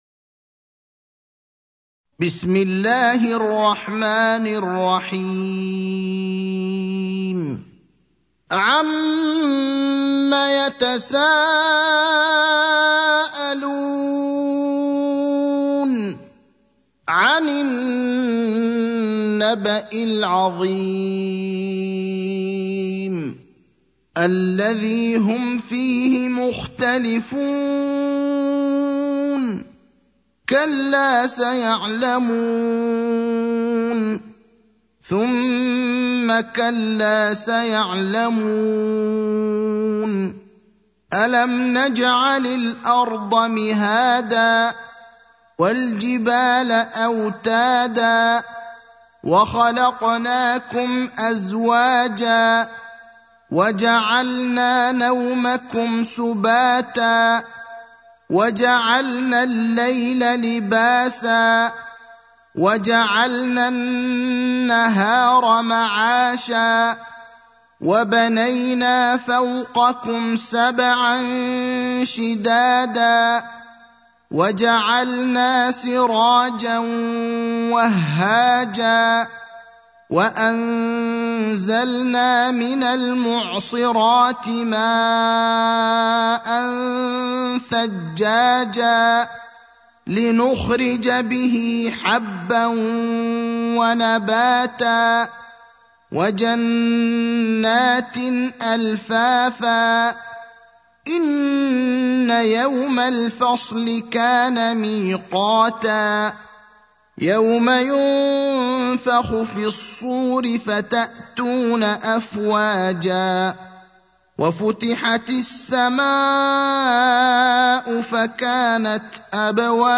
المكان: المسجد النبوي الشيخ: فضيلة الشيخ إبراهيم الأخضر فضيلة الشيخ إبراهيم الأخضر النبأ (78)